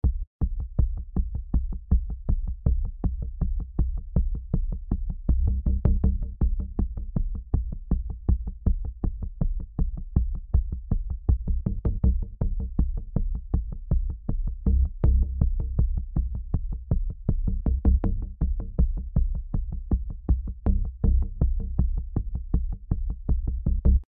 Alles beginnt mit einer Sequenz aus Spectrasonics Omnisphere 2 mit dem Namen „Poppy Pluckers“:
Der Sound basiert auf Casio- und Jupiter 8 – Samples:
Um ein tieffrequentes Pulsieren zu erzeugen, spiele ich den Loop auf C1 ein. Per Aftertouch moduliere ich das Filter vorsichtig, um etwas Abwechslung in die Monotonie zu bringen.
Die Modulation erstreckt sich über 16 Takte.